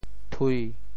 梯 部首拼音 部首 木 总笔划 11 部外笔划 7 普通话 tī 潮州发音 潮州 tui1 文 中文解释 梯〈名〉 (形声。